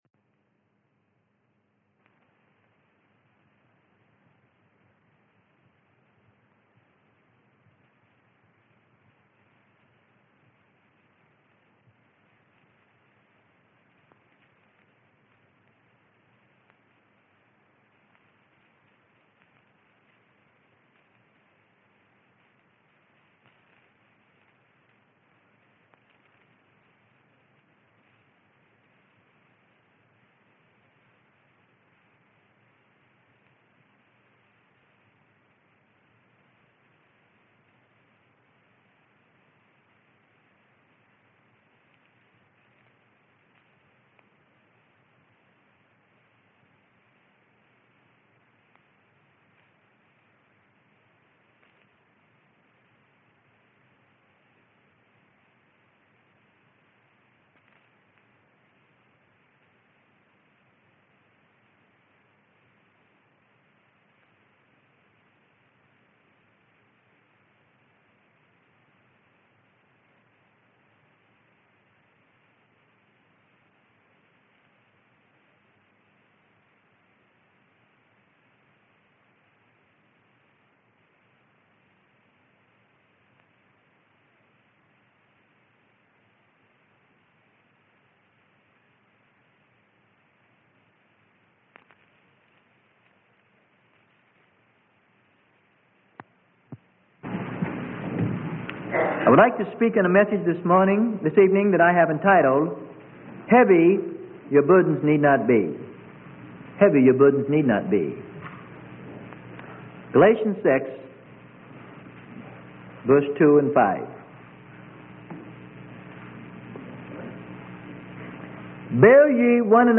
Sermon: Be Thus Minded - Phil 3:14-15 - Freely Given Online Library